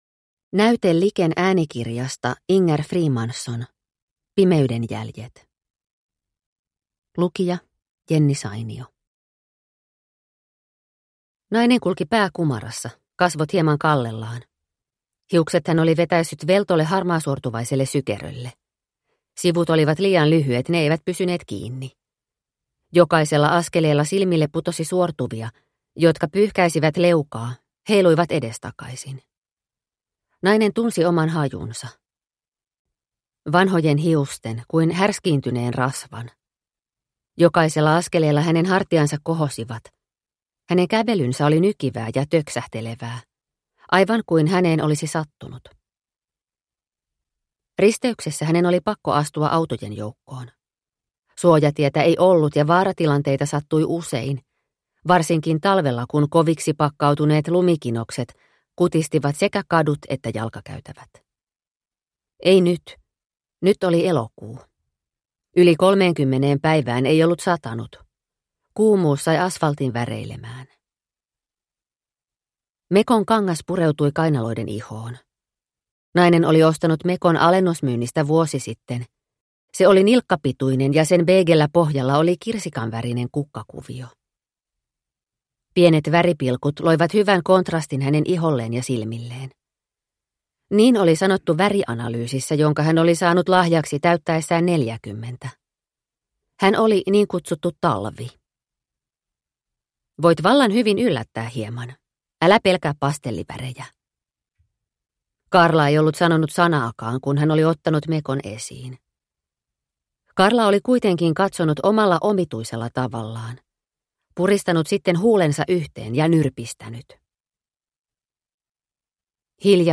Pimeyden jäljet – Ljudbok – Laddas ner